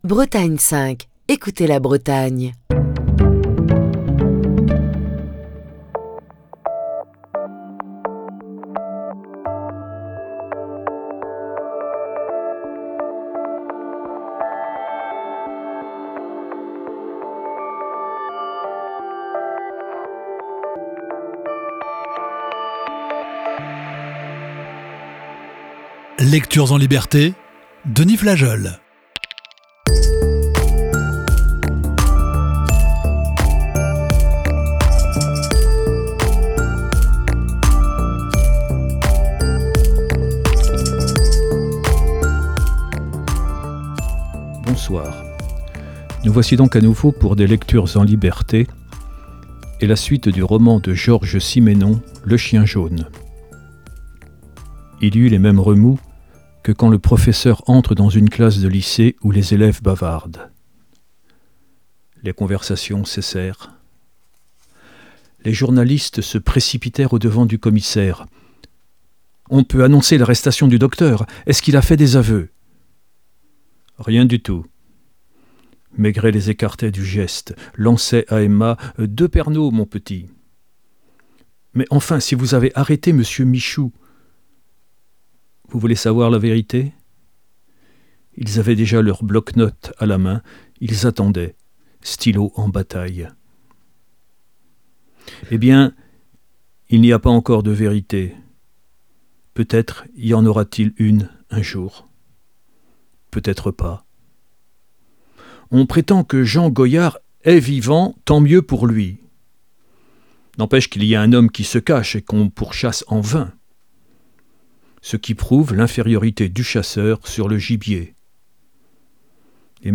Voici ce soir la sixième partie de ce récit.